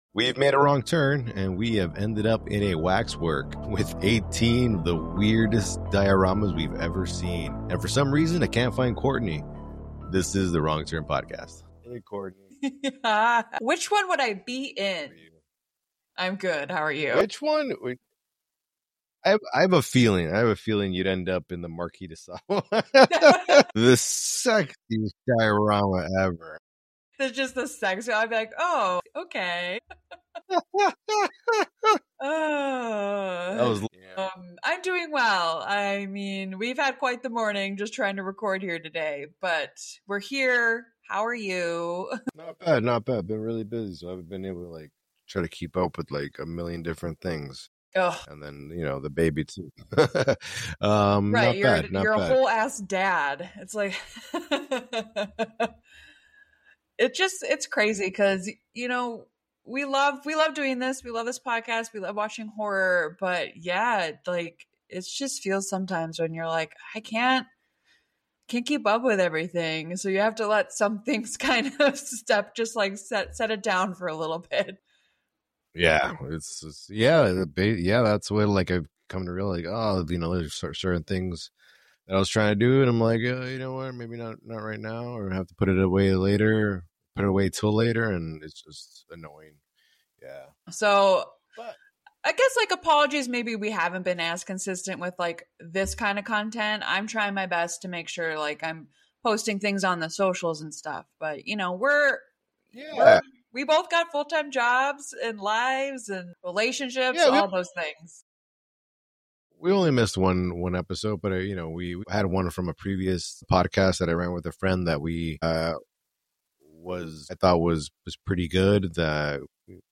Expect lively banter, creepy trivia, and their personal takes on the film's twists and turns.